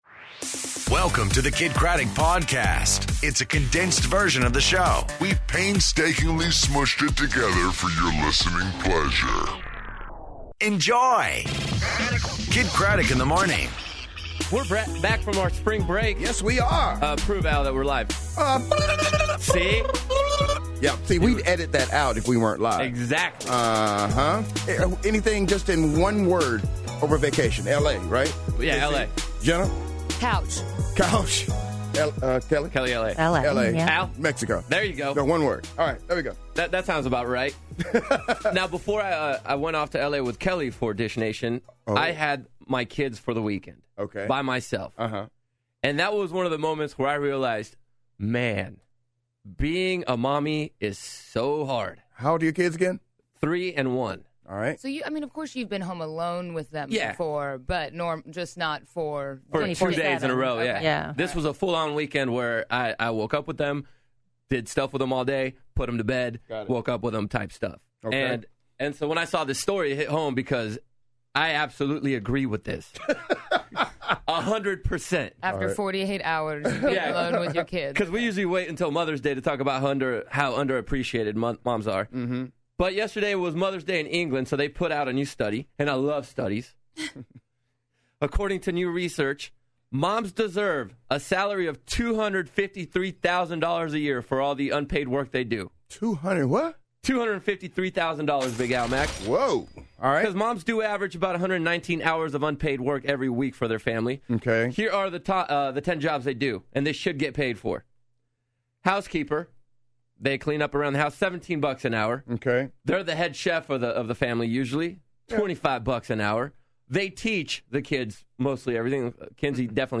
Vacation Stories, Andy Grammer In Studio, And The Best Jokes Ever!